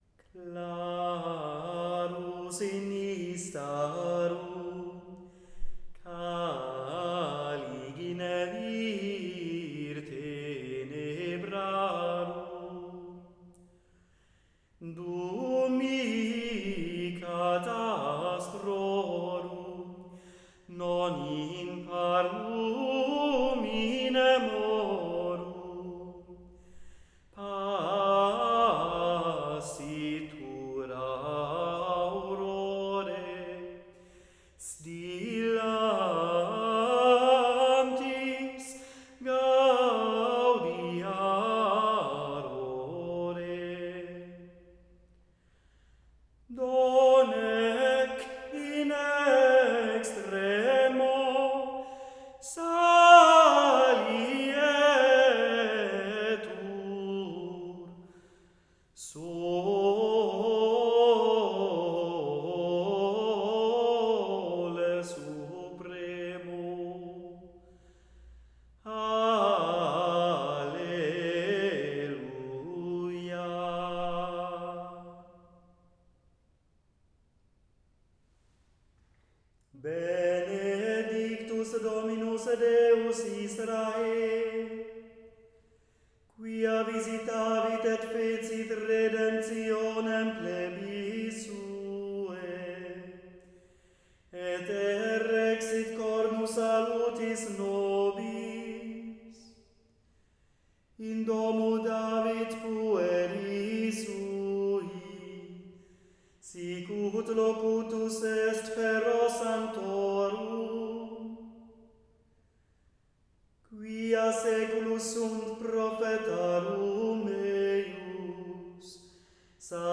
Benedictus-Antiphon_Clarus_in_istarum.wav